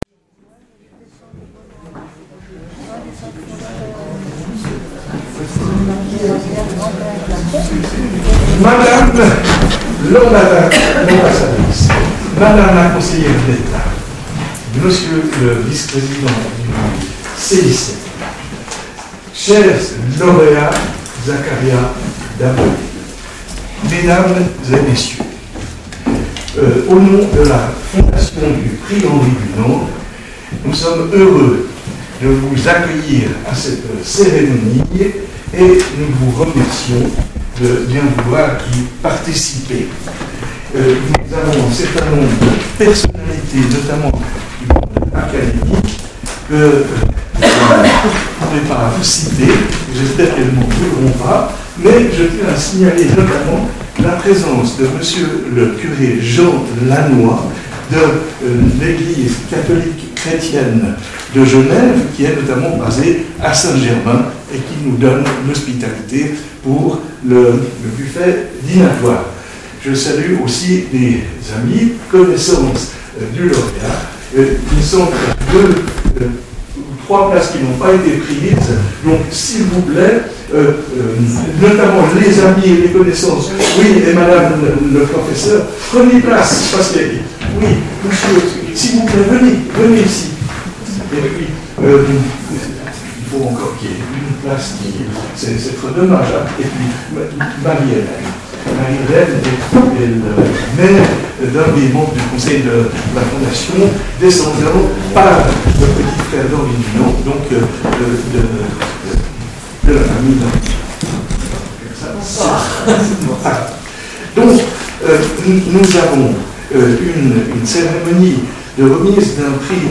Enregistrement réalisé le jeudi 20 novembre 2025 à la salle de l'Alabama, à Genève Durée de l'enregistrement